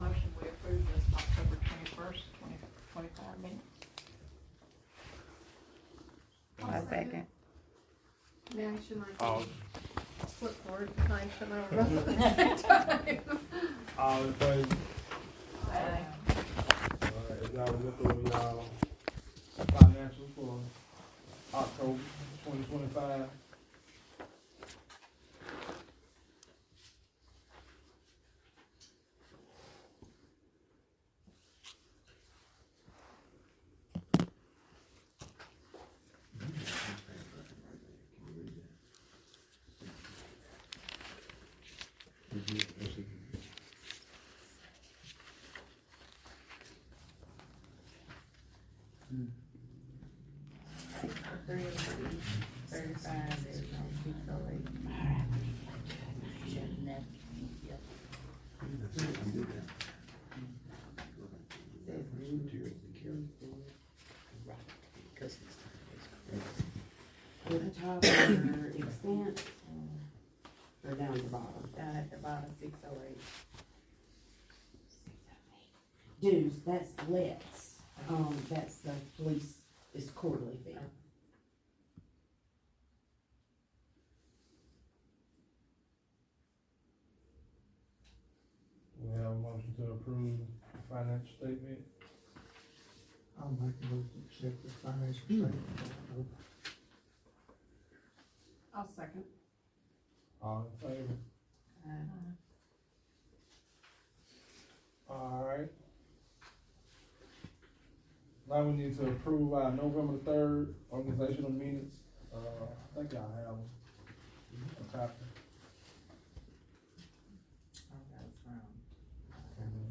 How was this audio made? Audio of the Nov. 18, 2025, Rockford Town Council meeting.